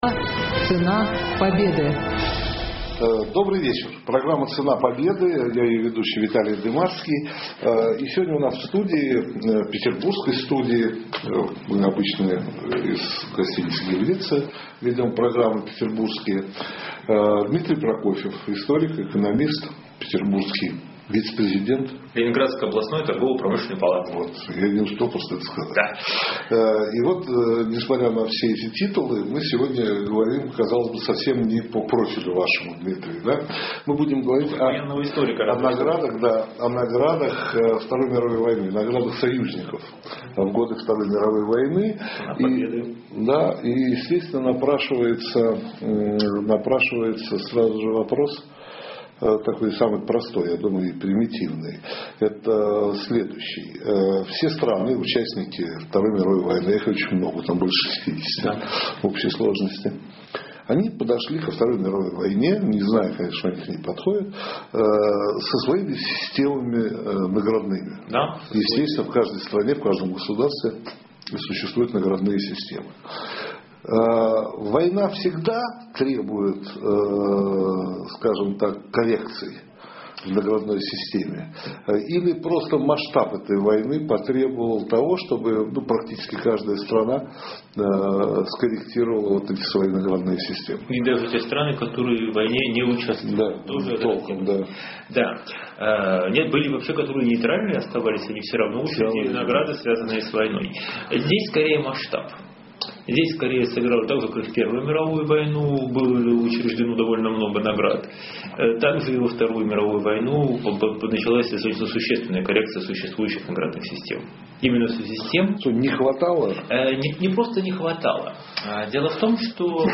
И сегодня у нас в студии, петербургской студии…